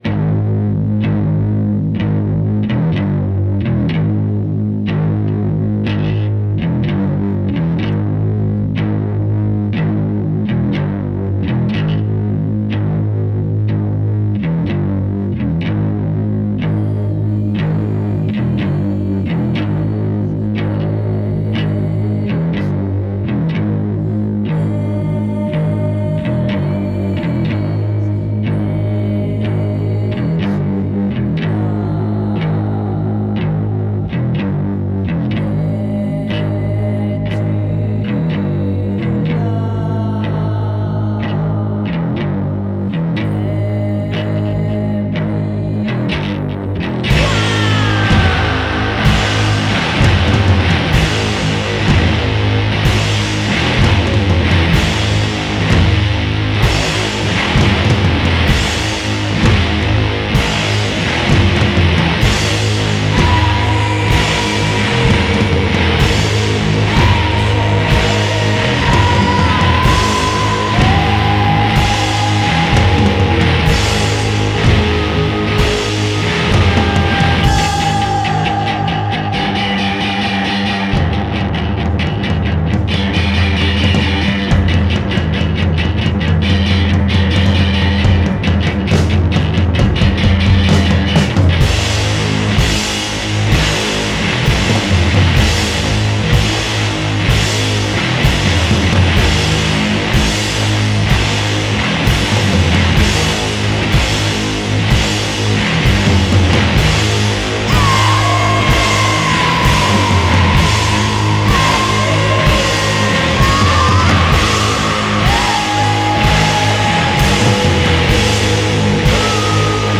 duo féminin basse-batterie